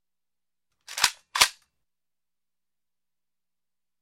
Звуки автомата Калашникова
Звук перезарядки автомата